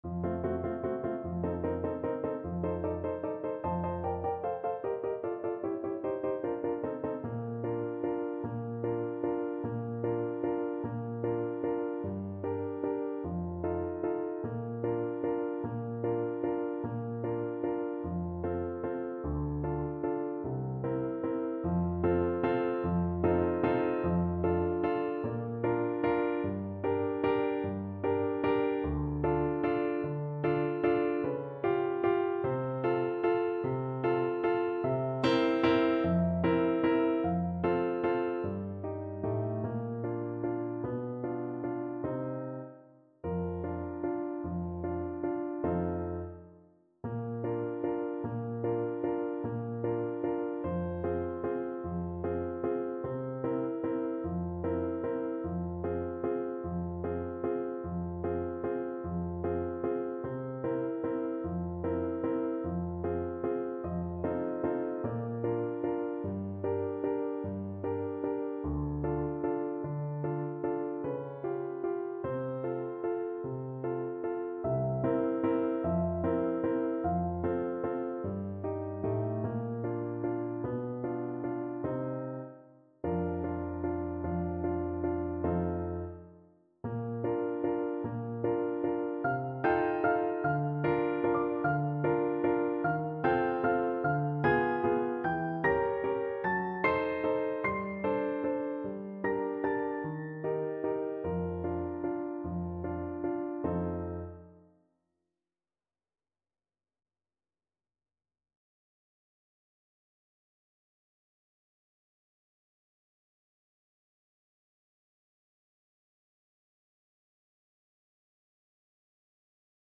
Adagio =50
Classical (View more Classical Clarinet Music)